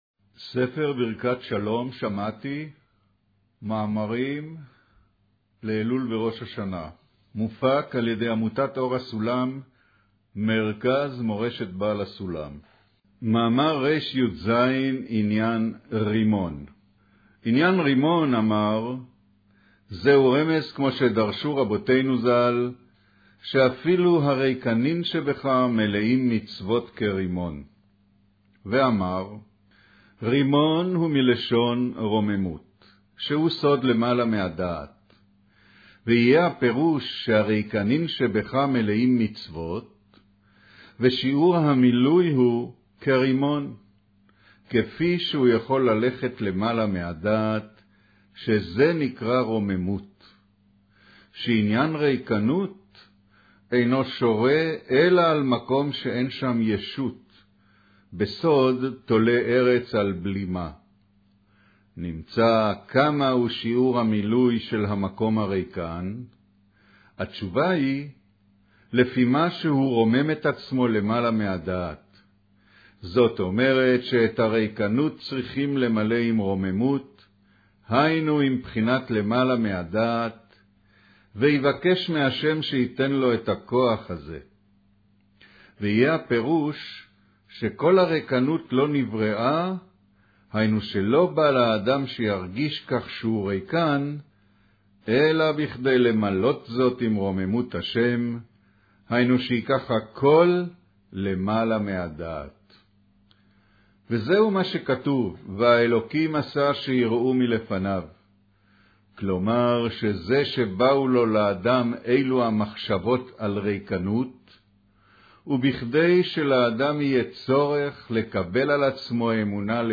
אודיו - קריינות מאמר עניין רימון